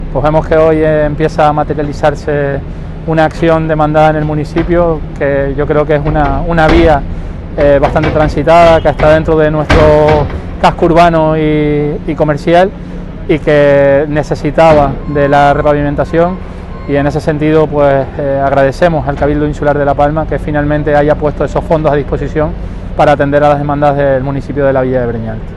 Declaraciones Jonathan Felipe.mp3